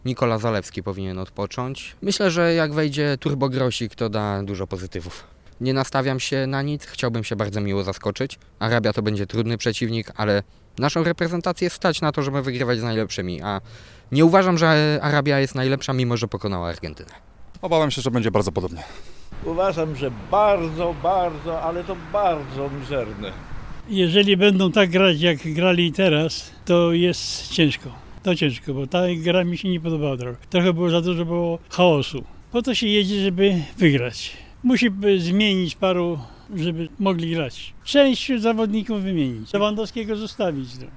Poprosiliśmy mieszkańców naszego regionu o podzielenie się swoimi przewidywaniami co do następnego meczu, tym razem z Arabią Saudyjską, która spektakularnie pokonała reprezentację Argentyny 2:1.